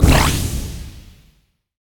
Sfx Platform Blast Start Sound Effect
sfx-platform-blast-start.mp3